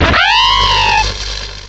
cry_not_luxio.aif